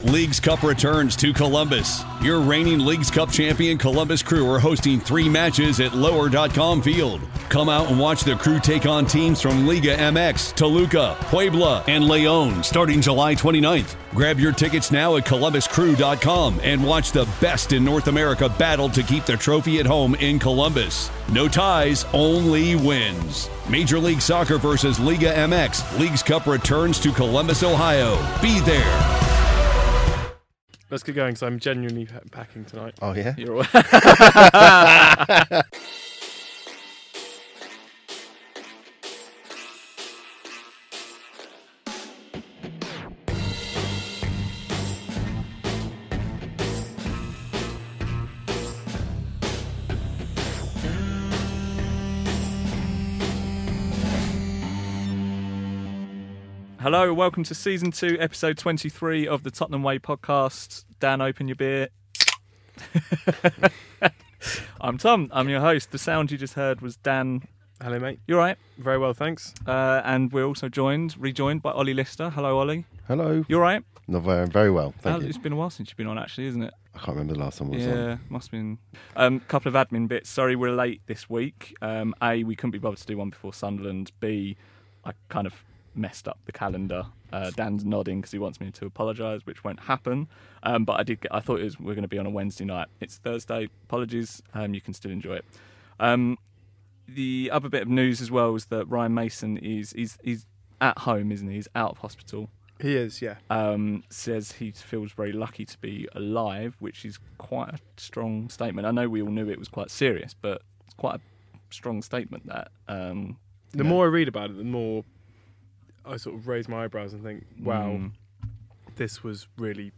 Be warned, we got a bit drunk and sloppy, perhaps in honour of our last two performances.